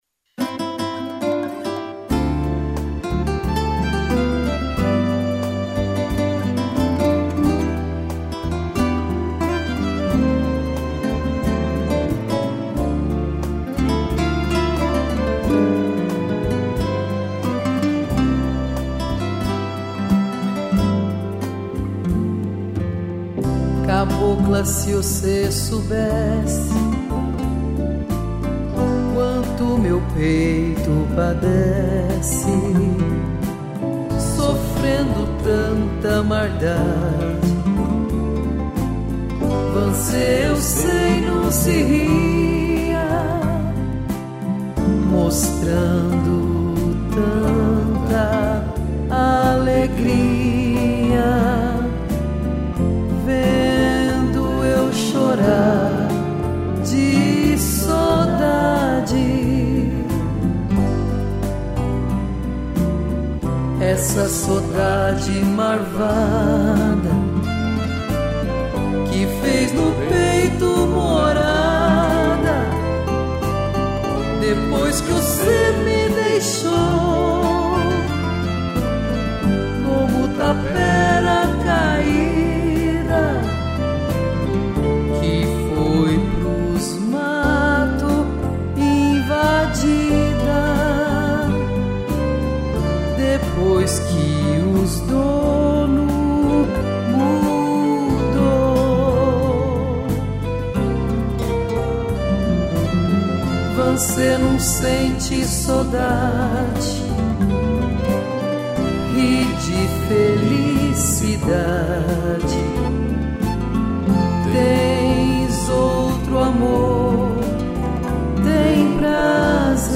violino, cello